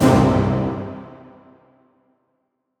DDW2 ORCHESTRA 1.wav